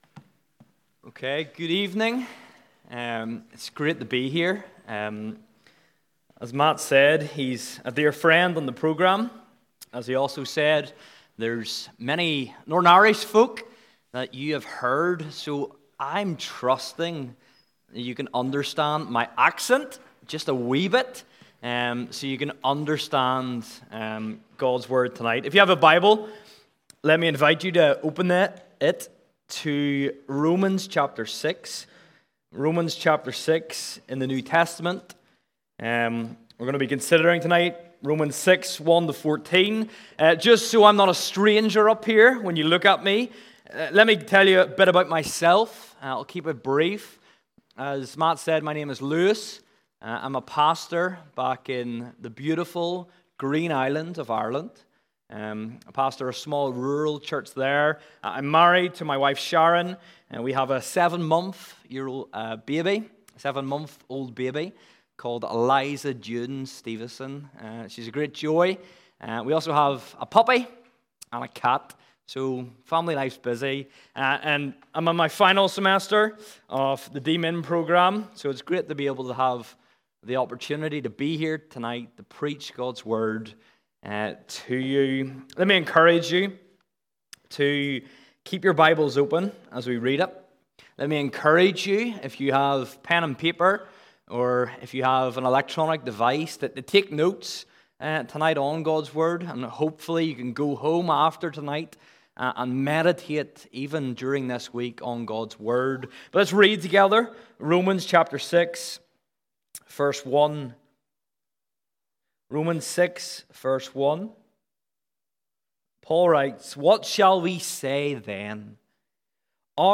January 9, 2026 - Sermon